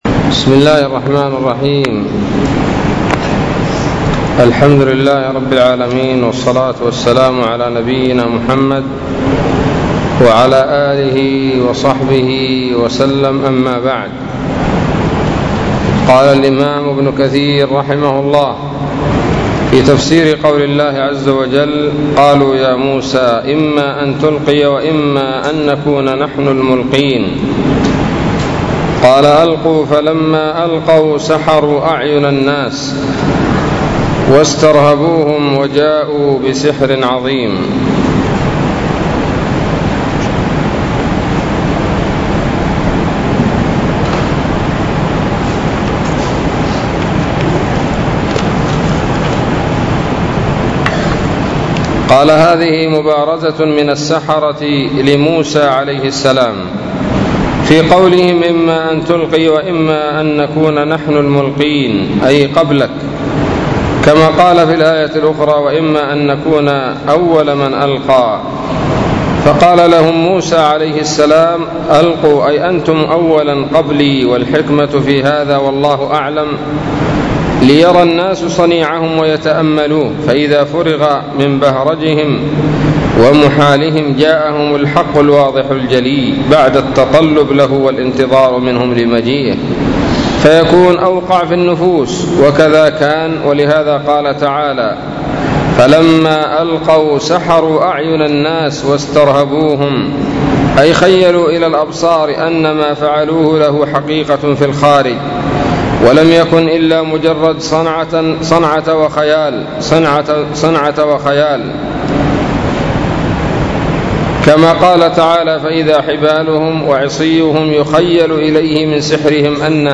الدرس الأربعون من سورة الأعراف من تفسير ابن كثير رحمه الله تعالى